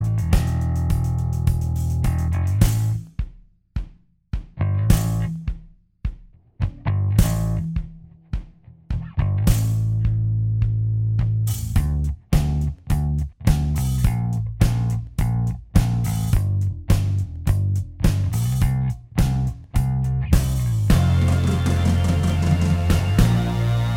Minus Lead Guitar And Solo